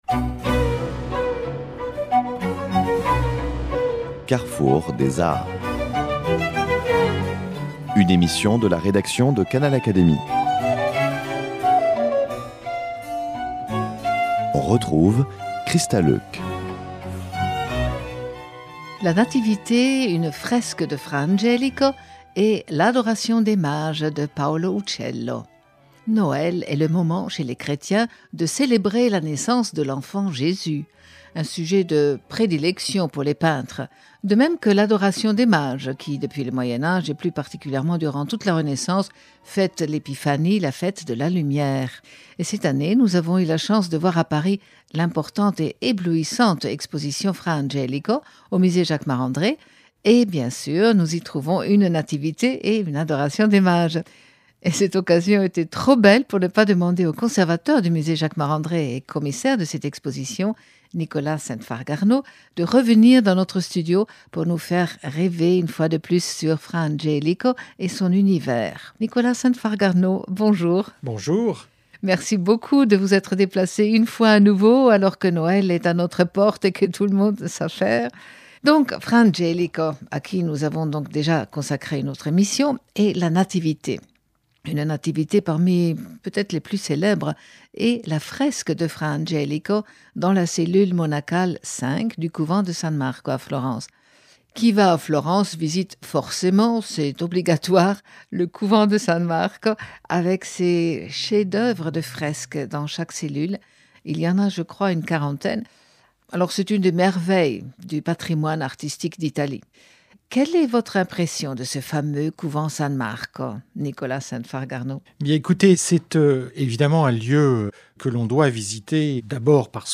Une éblouissante exposition Fra Angelico au Musée Jacquemart-André à Paris nous donne l’occasion de revenir sur deux œuvres de ces maîtres toscans. Entretien